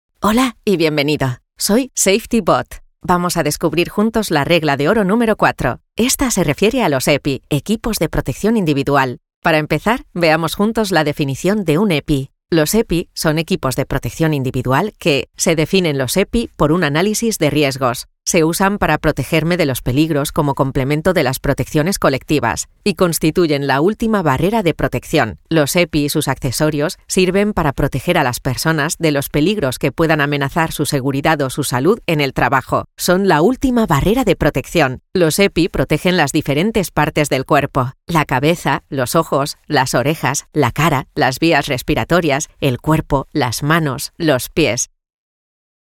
Female
She is able to record bass and high tones, corporative and natural narrations, resulting very versatile.
Words that describe my voice are elegant voice, compelling voice, corporative voice.
0714elearning_epi_m.mp3